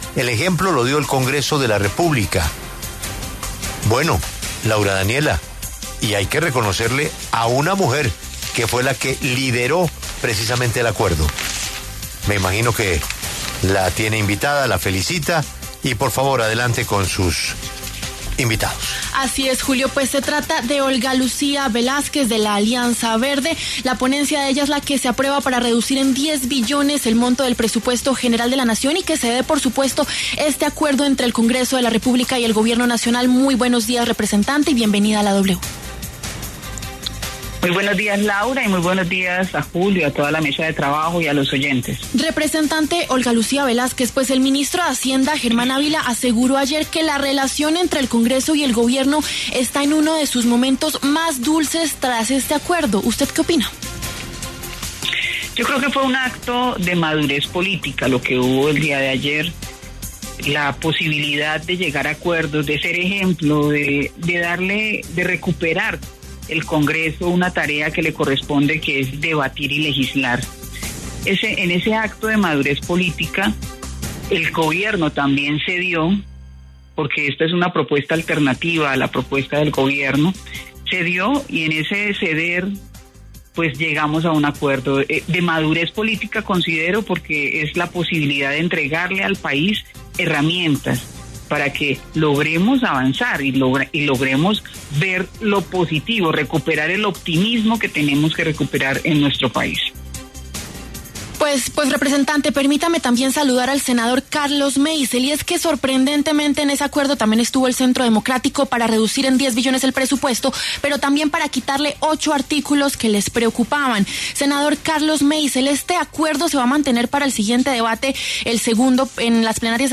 La representante Olga Lucía Velásquez, de la Alianza Verde y autora de la ponencia del consenso sobre el Presupuesto para 2026 pasó por los micrófonos de La W. También habló el senador Carlos Meisel, del Centro Democrático.